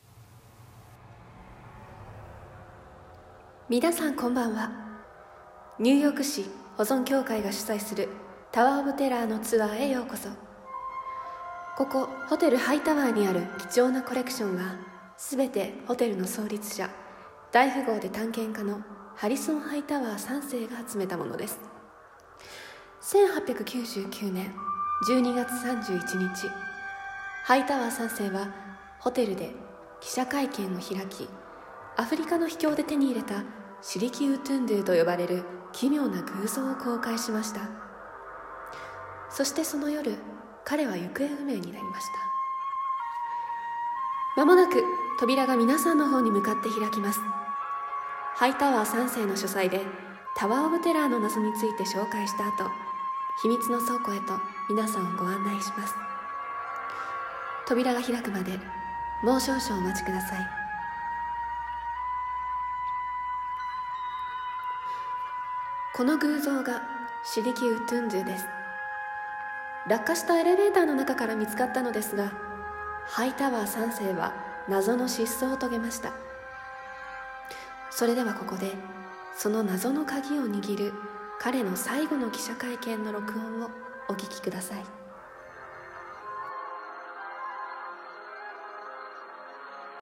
タワーオブテラー アナウンス